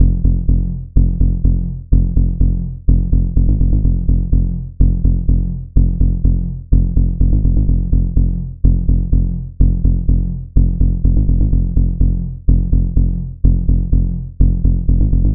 • Tech House Bass Rhythm Punchy - F maj.wav
Loudest frequency 82 Hz
Tech_House_Bass_Rhythm_Punchy_-_F_maj_35z.wav